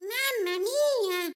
Baby Mario being retrieved from lava in Mario Kart 8.
MK8_Baby_Mario_-_Mama_mia.oga